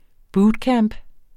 Udtale [ ˈbuːdˌkæːmb ]